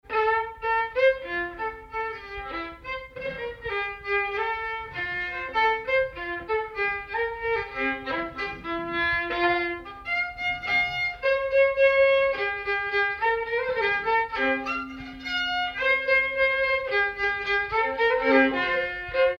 Danse
Danse Cette Pièce musicale inédite a pour titre "Danse".
violon
circonstance : bal, dancerie